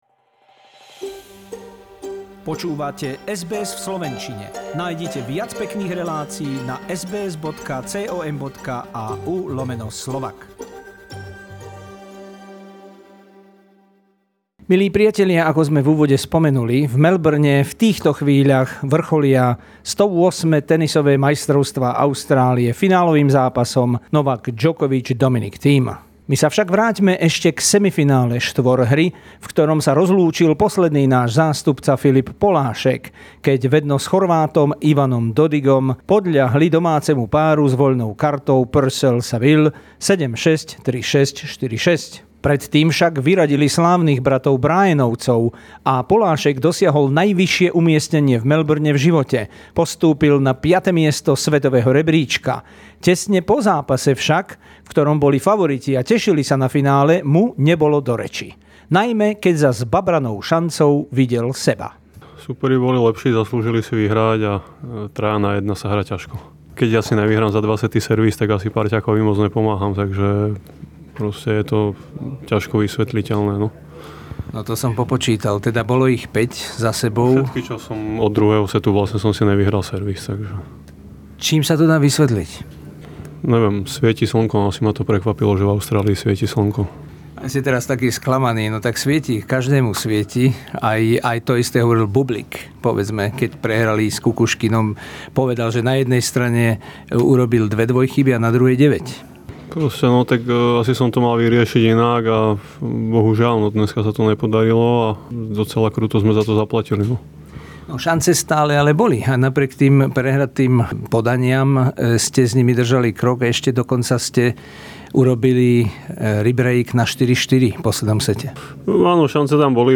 Interview with Filip Polasek, world doubles number 5 in ranking after a shock loss in the semifinal of Australian Open 2020.